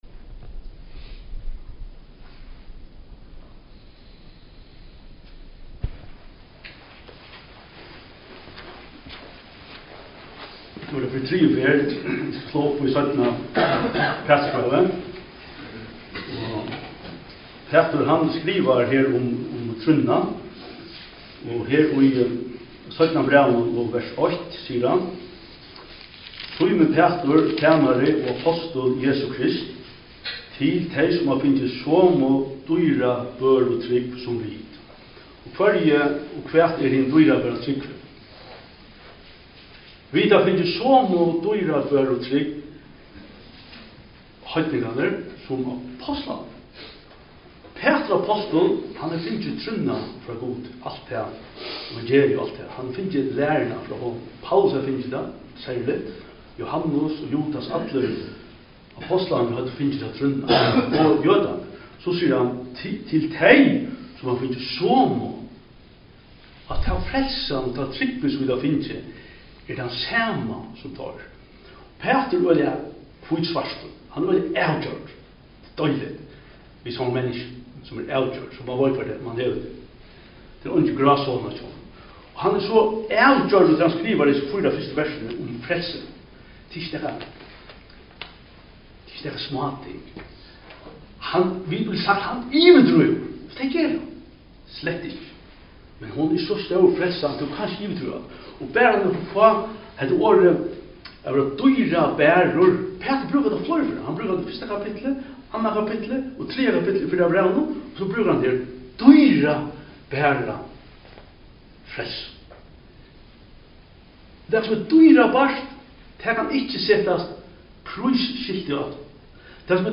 Talur (Fø)